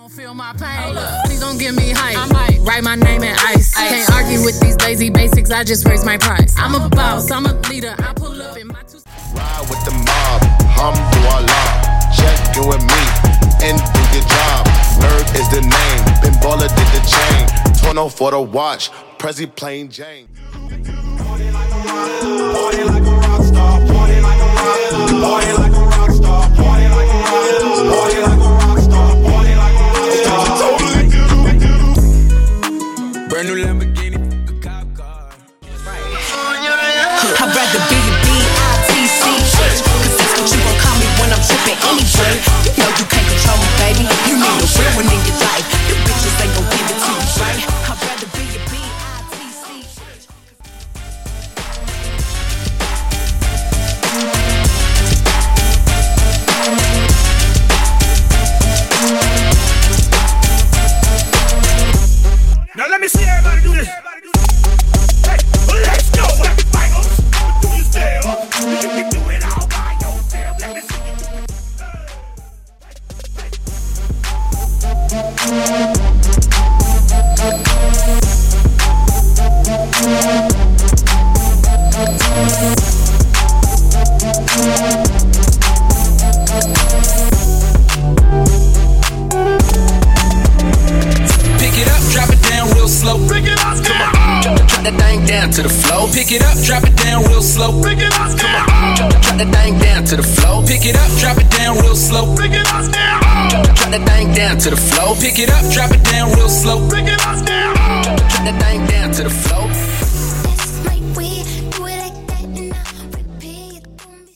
FUTURE HOUSE , MASHUPS